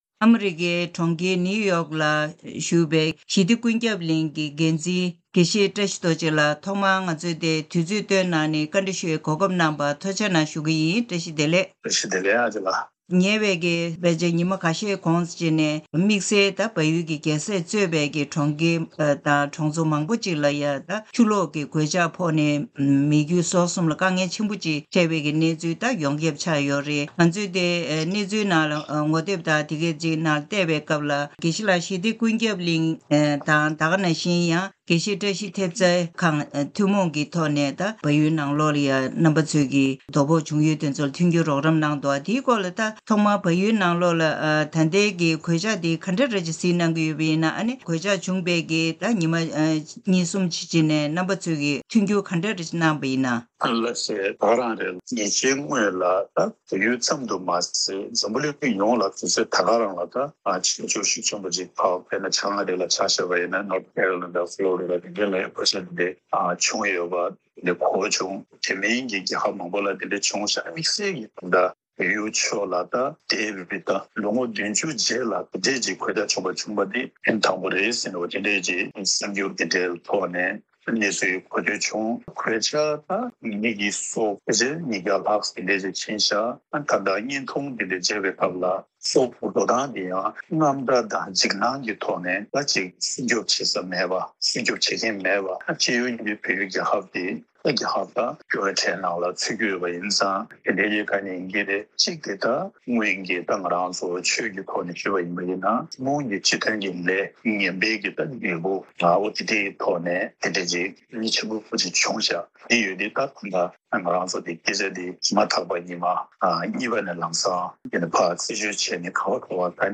གནས་འདྲིའི་ལེ་ཚན་ནང་།